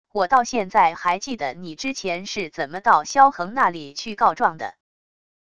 我到现在还记得你之前是怎么到萧恒那里去告状的wav音频生成系统WAV Audio Player